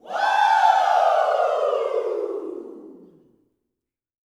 WOO  08.wav